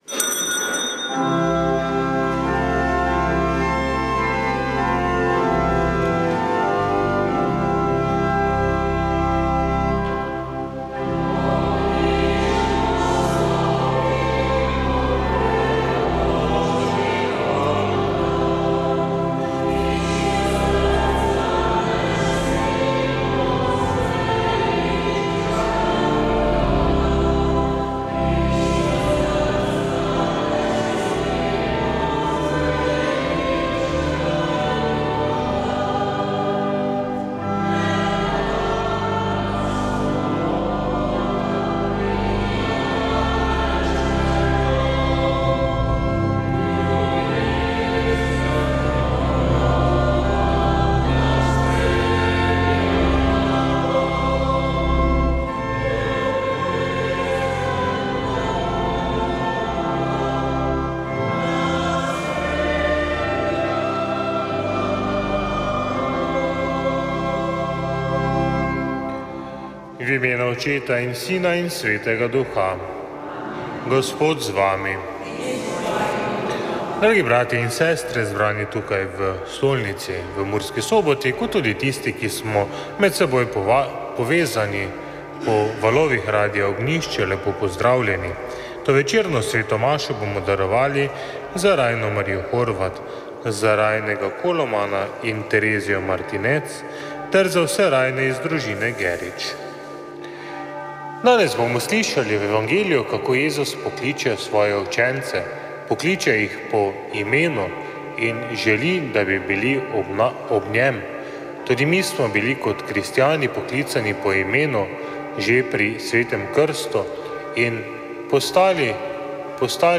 Sveta maša
Sv. maša iz stolne cerkve sv. Nikolaja v Murski Soboti 10. 7.